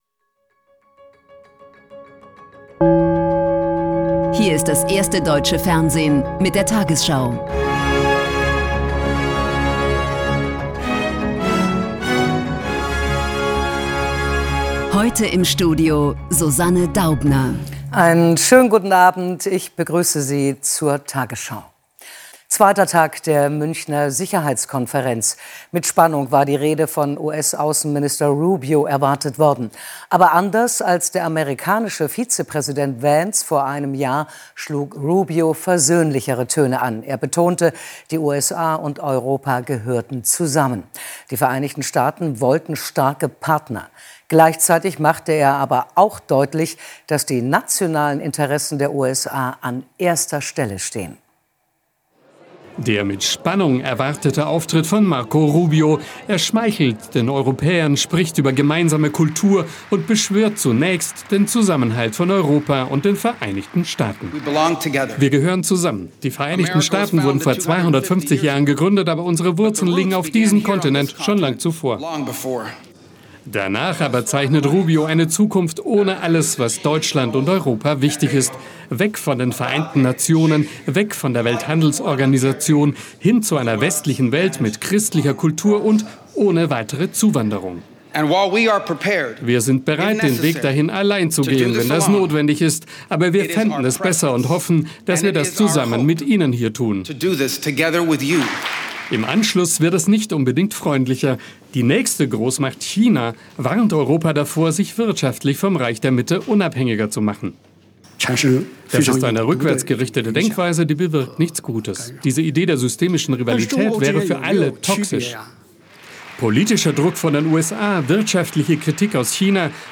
tagesschau 20:00 Uhr, 14.02.2026 ~ tagesschau: Die 20 Uhr Nachrichten (Audio) Podcast